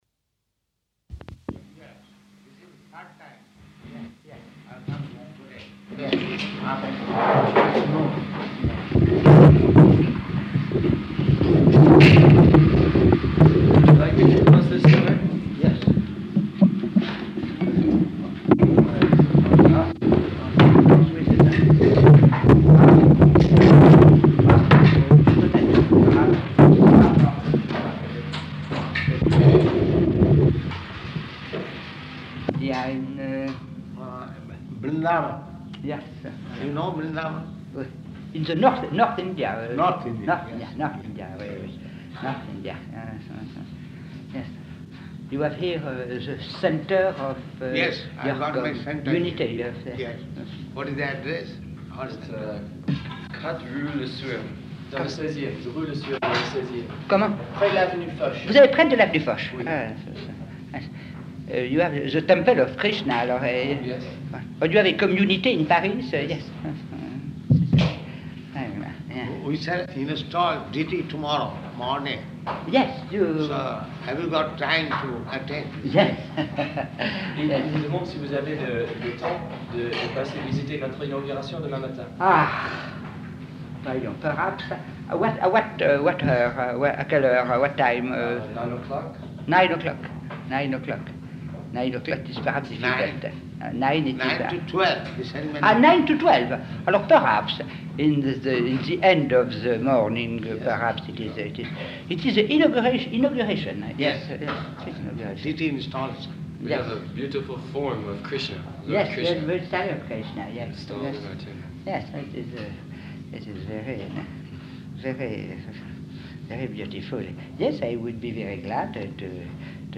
Room Conversation with Cardinal Danielou
Room Conversation with Cardinal Danielou --:-- --:-- Type: Conversation Dated: August 9th 1973 Location: Paris Audio file: 730809R2.PAR.mp3 Prabhupāda: Yes, this is the third time.
[heavy microphone noises] [break] Prabhupāda: Vṛndāvana.